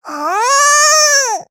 voices / heroes / jp
Taily-Vox_Casting2_jp.wav